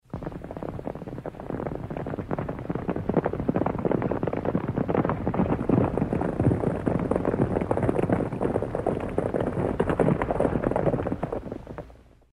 Звук табуна лошадей